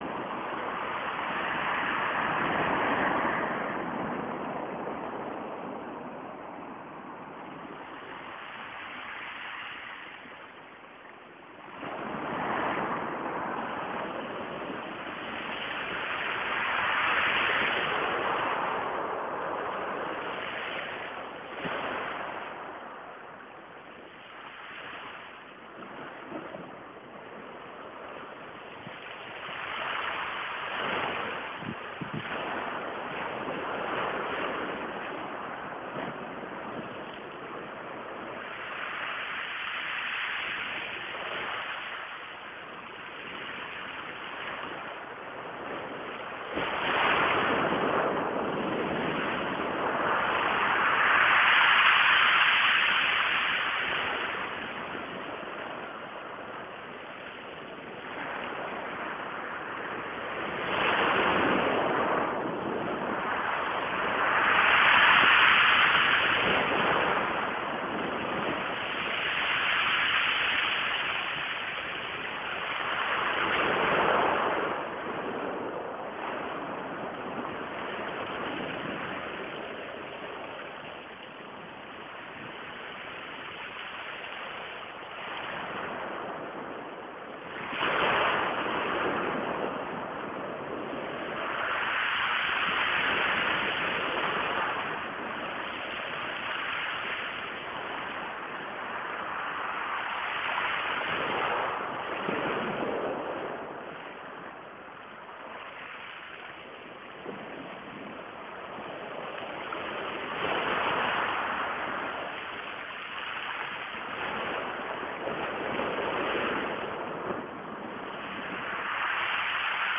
Today, at the bay, I met Kolga ("the cold one") or Hrönn (wavy one).